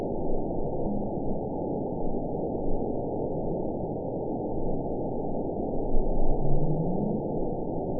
event 910055 date 01/13/22 time 11:50:05 GMT (3 years, 5 months ago) score 9.50 location TSS-AB08 detected by nrw target species NRW annotations +NRW Spectrogram: Frequency (kHz) vs. Time (s) audio not available .wav